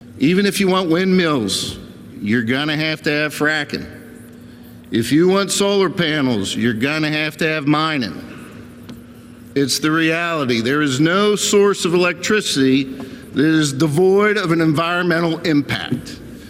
In comments on the floor of the PA Senate, Pittman was critical of Shapiro and the deal with PJM.